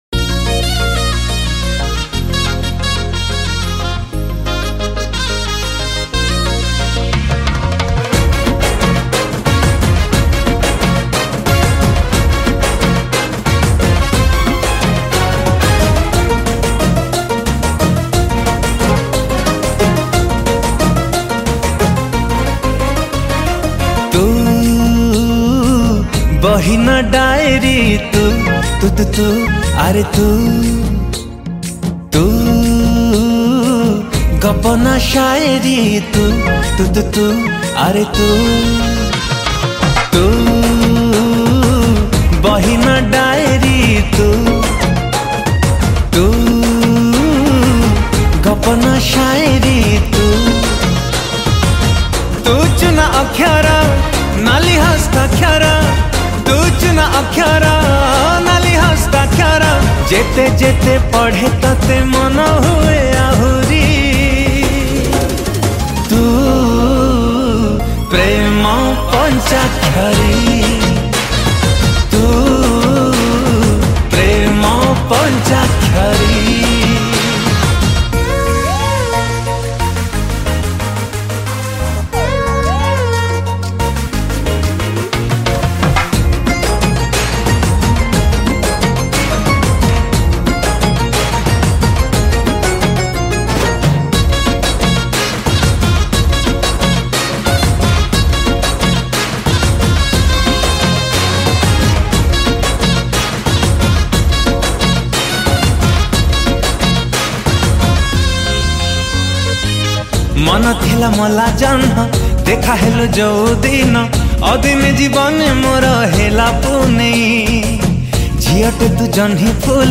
(Studio Version)